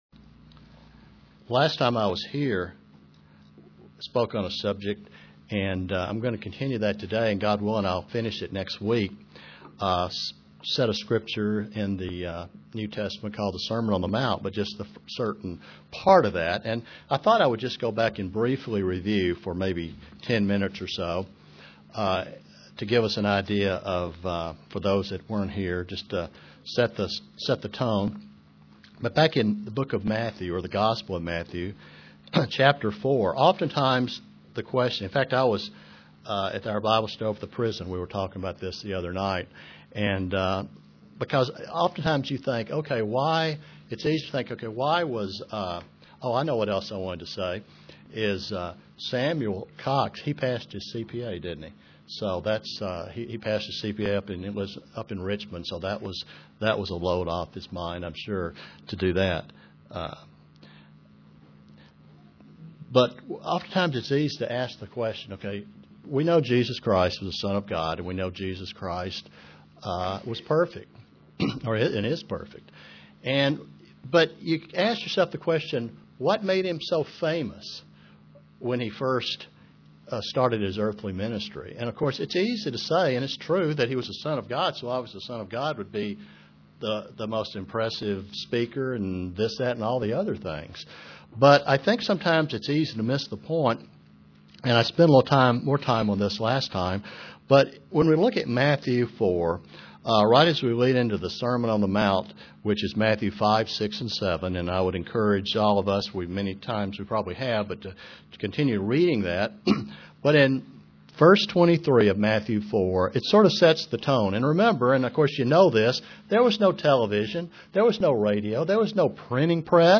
Print Continuing study of the beatitudes UCG Sermon Studying the bible?